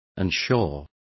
Complete with pronunciation of the translation of unsure.